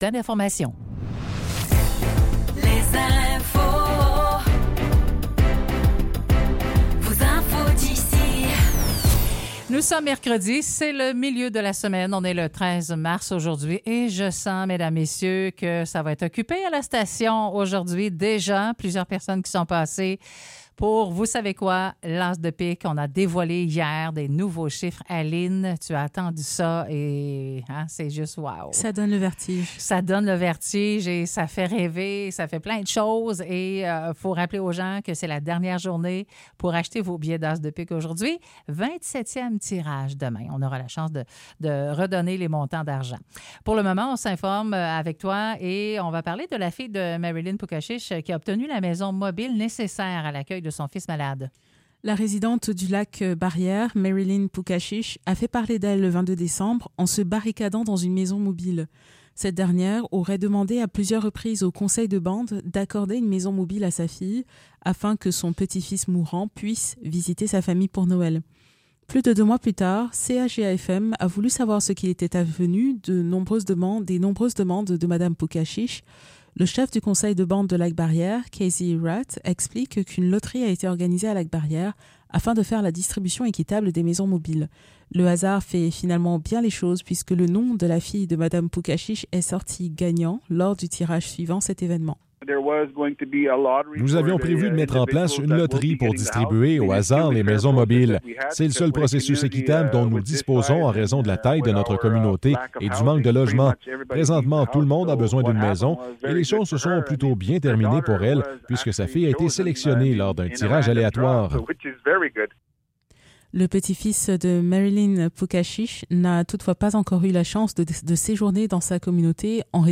Nouvelles locales - 13 mars 2024 - 9 h